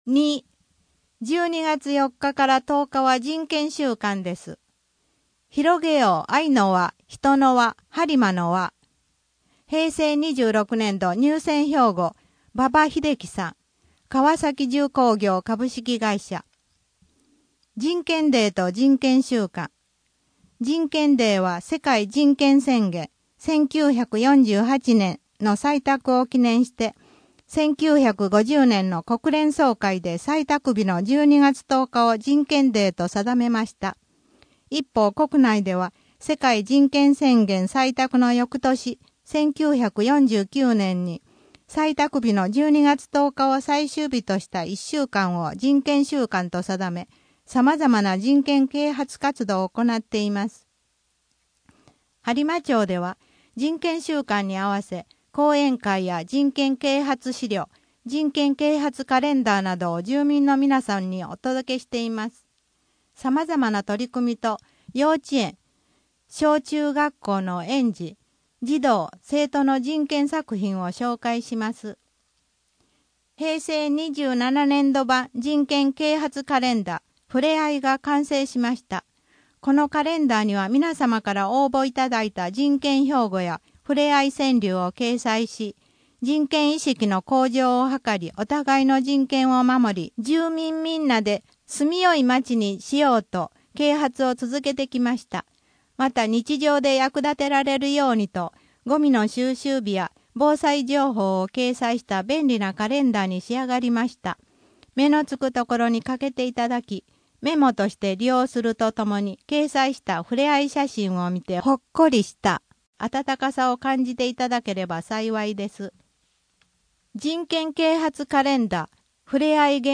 声の「広報はりま」12月号
声の「広報はりま」はボランティアグループ「のぎく」のご協力により作成されています。